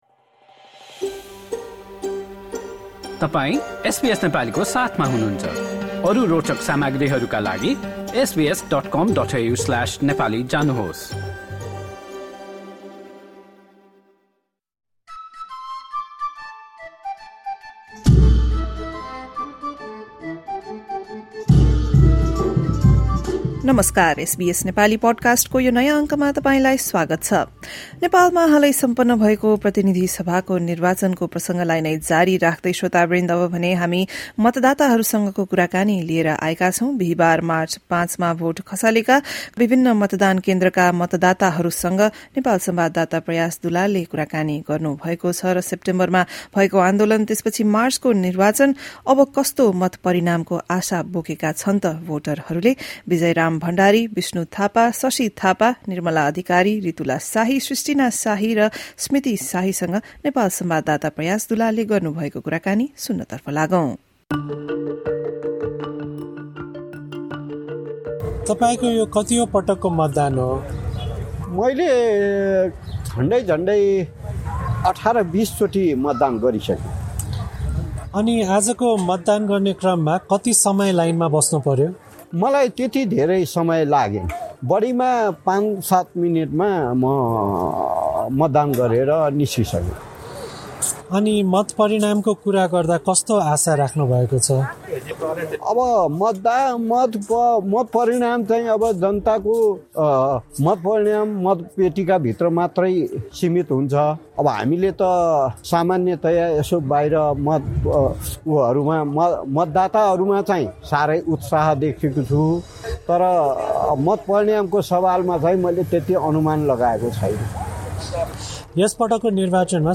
A voter shows her inked thumb after casting her ballot at a polling station during Nepal's parliamentary election in Kathmandu on March 5, 2026.